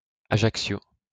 wymowa [aʒaksjo]; kors.